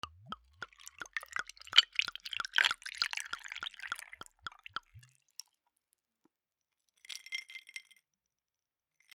ブランデーグラス 氷(ロックアイス) 水を注ぐ
酒 バー